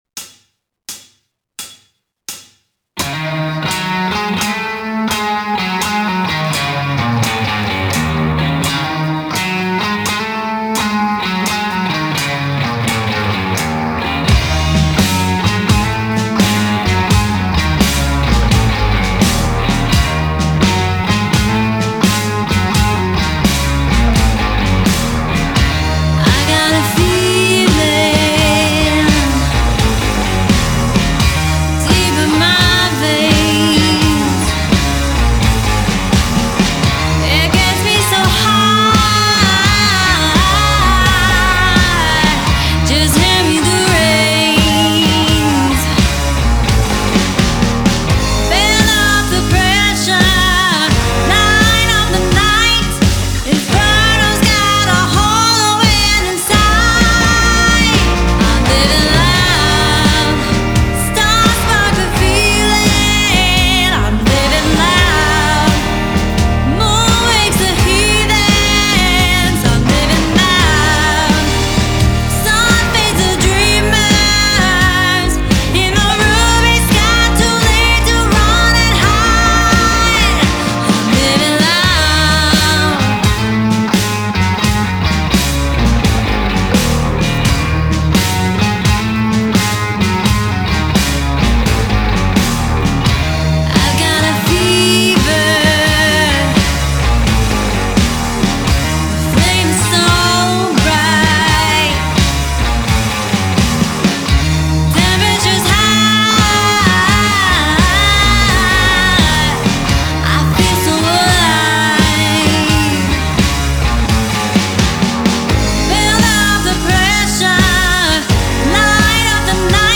Killer Vocals, Killer Guitar, Killer Rock !!
Genre: Blues, Blues Rock